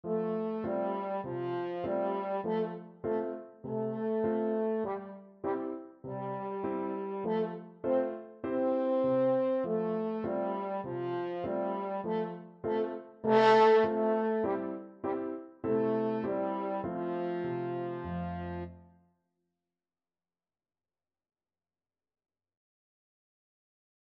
Allegretto
4/4 (View more 4/4 Music)